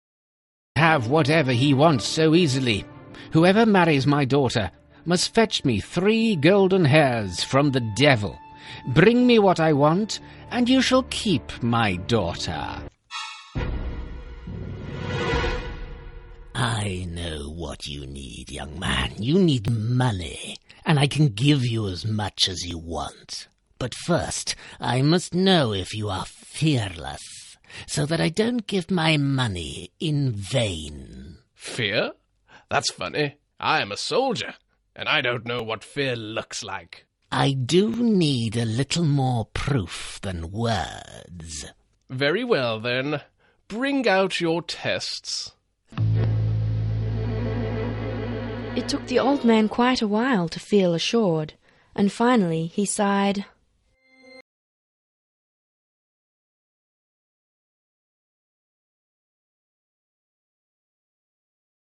Our audiobook publishing services provide professional narration, sound engineering, and production to create a high-quality listening experience that reflects your voice and message.